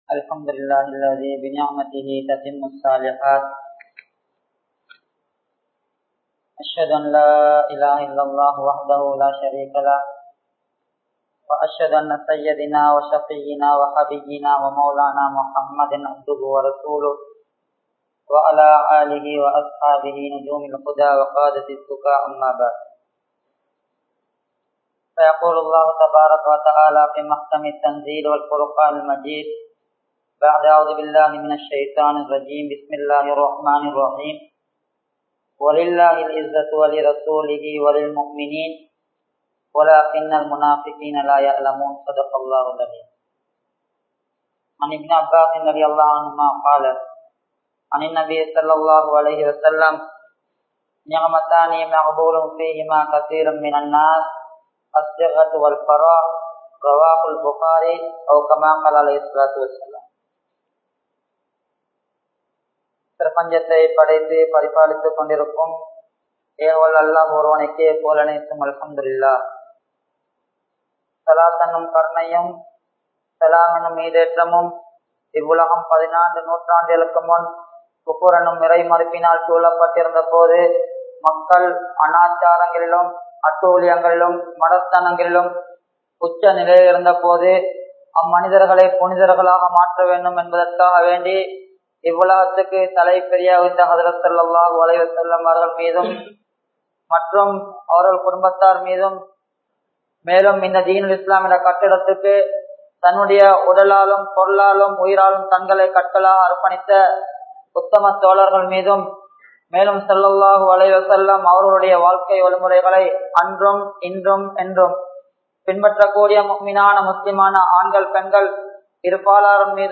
Vaalifarhal Entral Yaar? (வாலிபர்கள் என்றால் யார்?) | Audio Bayans | All Ceylon Muslim Youth Community | Addalaichenai